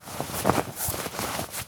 foley_cloth_light_fast_movement_01.wav